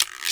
LongGuiro.wav